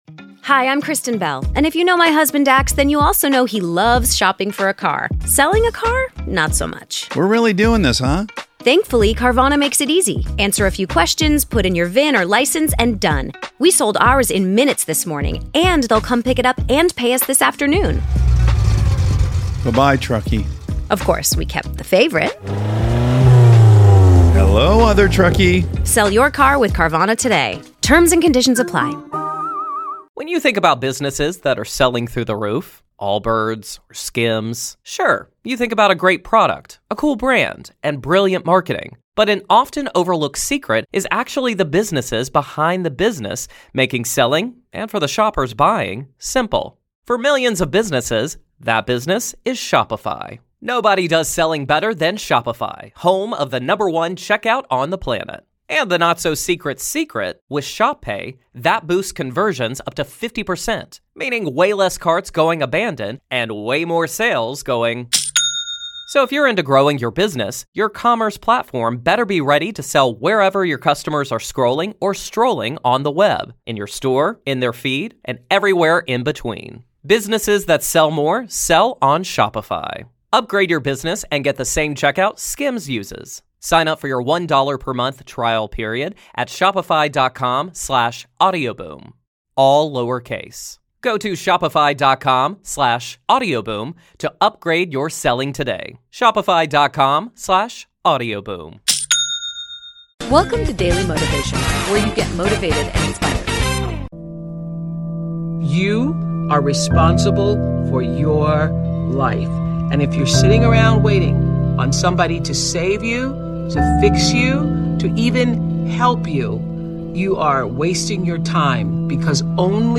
Speakers: Oprah Winfrey Tony Robbins Tom Bilyeu Jim Rohn Ed Myleet Gary Vee Les Brown Barack Obama